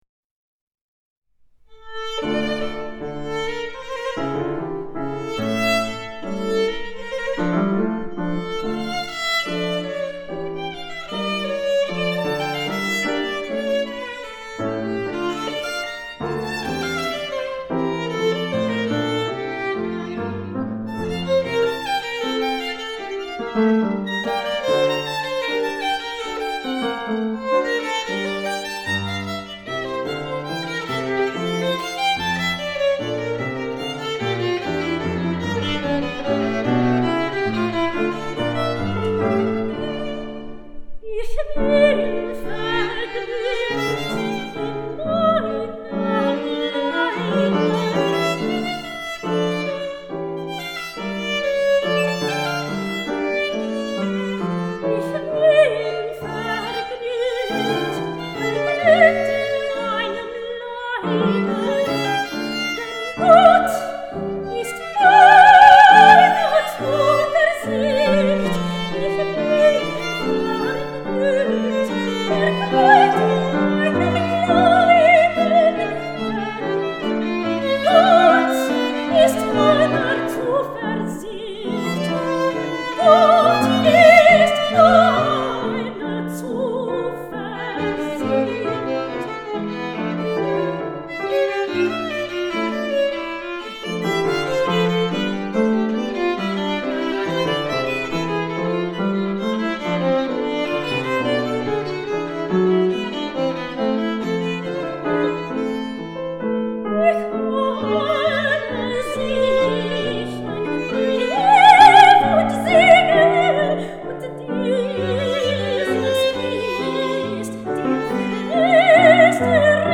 Soprano
violin
continuo
*live performances